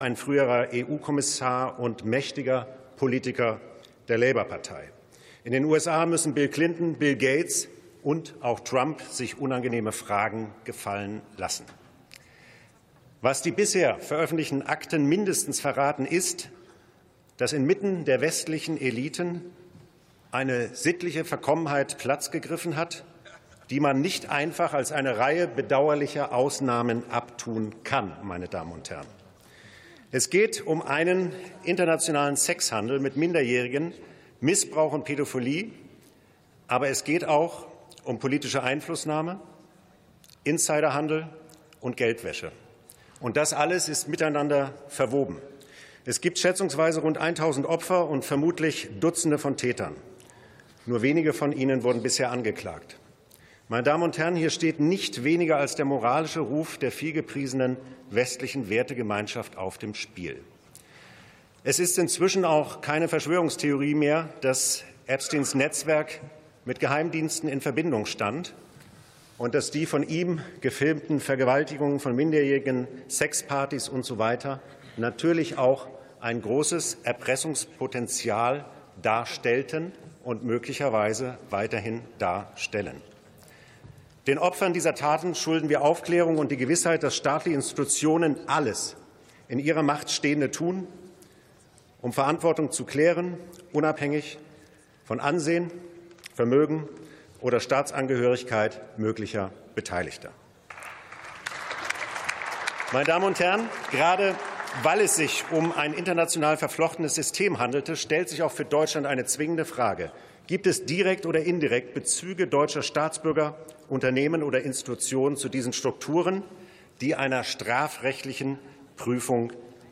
62. Sitzung vom 05.03.2026. TOP 16: Untersuchung deutscher Bezüge zu den Epstein-Akten ~ Plenarsitzungen - Audio Podcasts Podcast